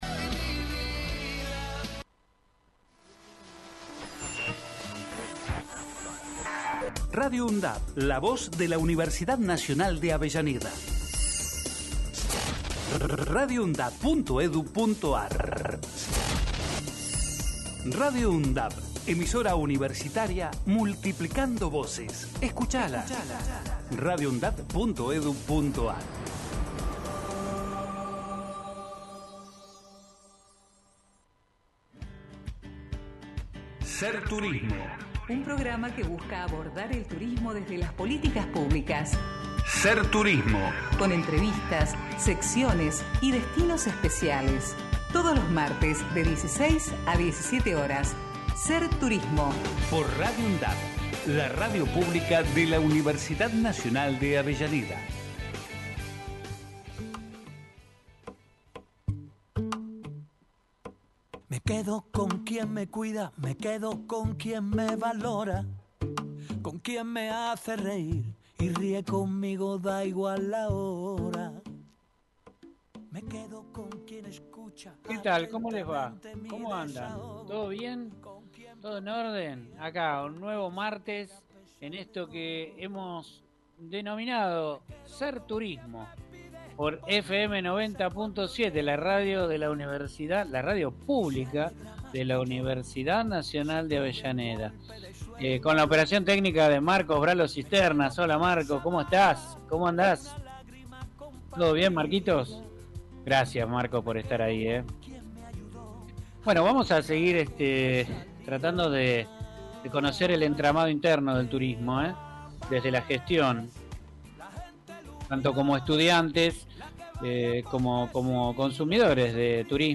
Con entrevistas, secciones y destinos especiales, todos los martes de 16 a 17 horas Integrantes